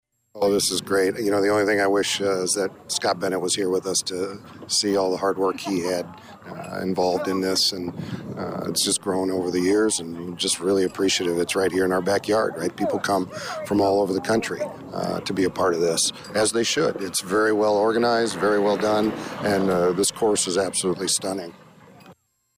This is the tenth year that Danville has hosted the tournament, and State Senator Paul Faraci was among those attending the opening ceremony…